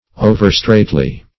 Search Result for " overstraitly" : The Collaborative International Dictionary of English v.0.48: Overstraitly \O`ver*strait"ly\, adv. Too straitly or strictly.